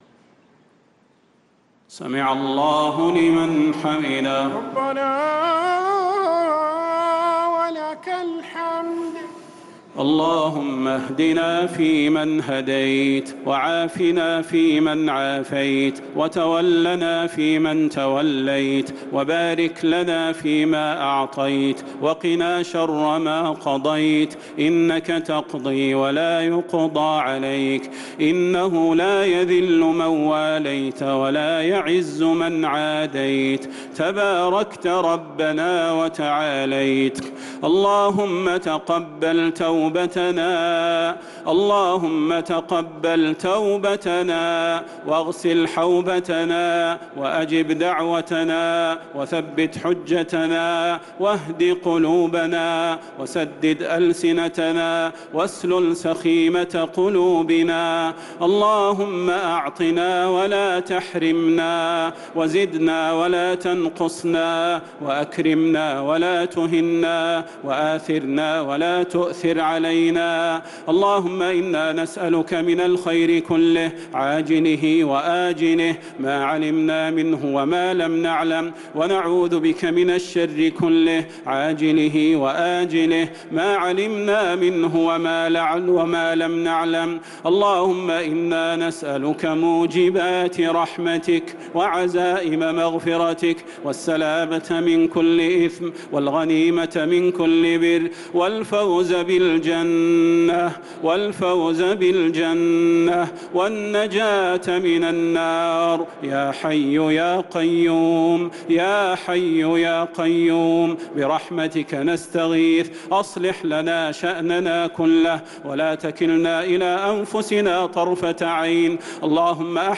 صلاة التراويح ليلة 6 رمضان 1445 للقارئ صلاح البدير - دعاء القنوت